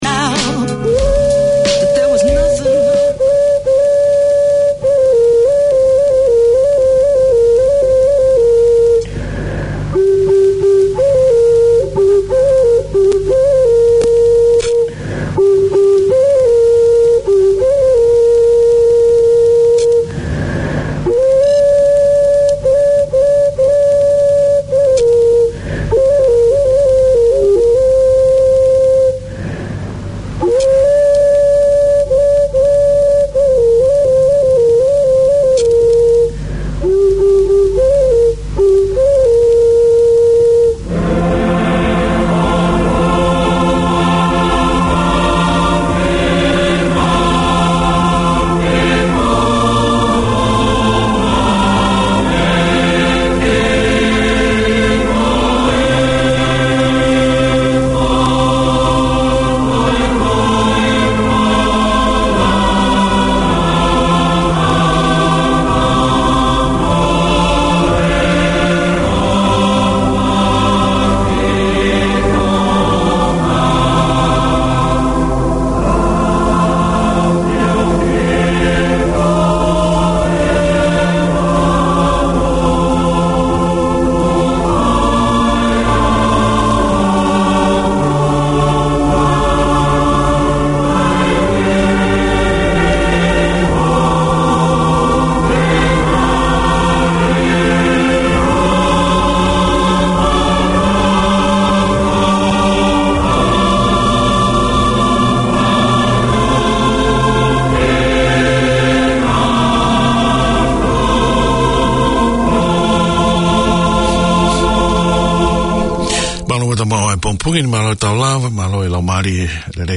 A new era in Tongan broadcasting, this is a talk show that focuses on community successes and debating issues from every angle relevant to Tongan wellbeing. Four mornings a week, the two hour programmes canvas current affairs of concern to Tongans and air in-depth interviews with Tongan figureheads, academics and successful Tongans from all walks of life.